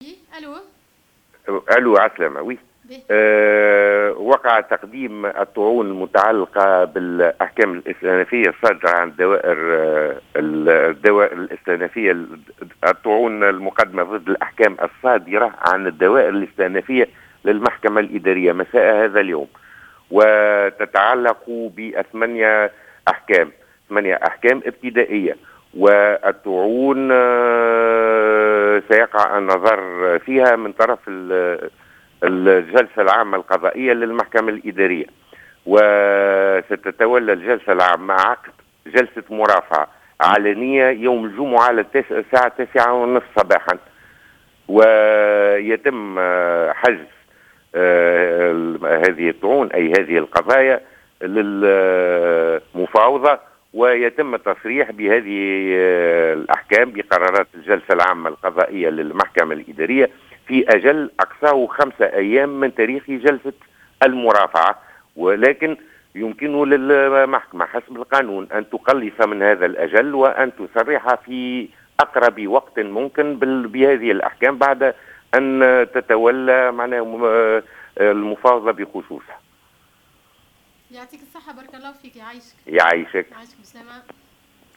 أكد الرئيس الأول للمحكمة الإدارية، محمد فوزي بن حماد في تصريح لجوهرة اف ام أن...